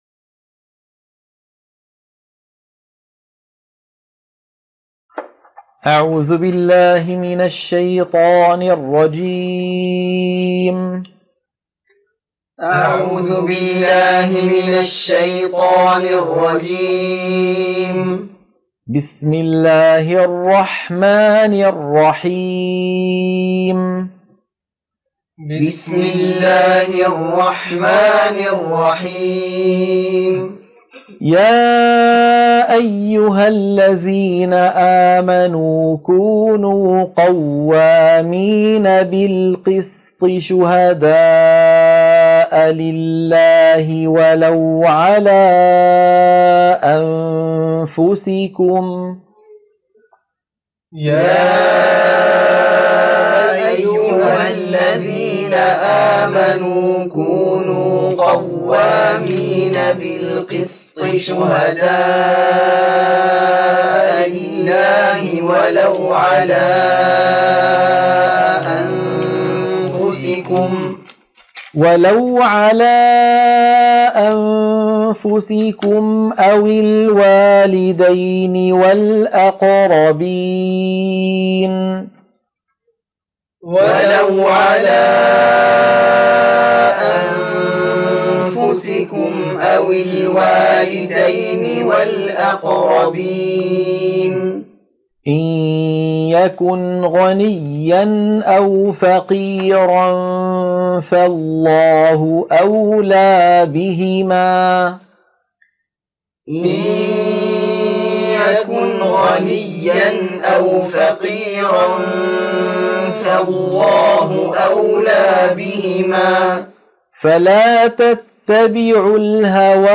تلقين سورة النساء - الصفحة 100 التلاوة المنهجية